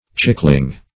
Search Result for " chickling" : The Collaborative International Dictionary of English v.0.48: Chickling \Chick"ling\ (ch[i^]k"l[i^]ng), n. [Chick + -ling.] A small chick or chicken.